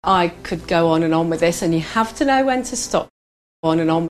A good way to illustrate this distinction is with native speaker recordings of the common phrase on and on, /ɒn ən ɒn/: